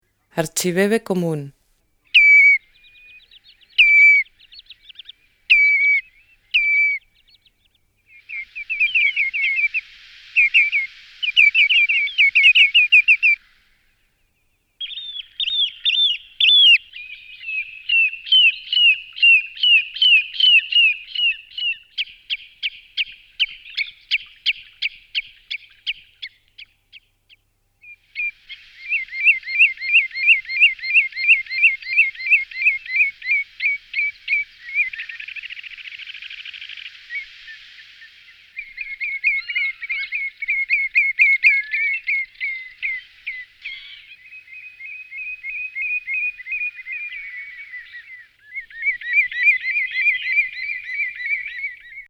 12_demo_2-16_Archibebe_Común.mp3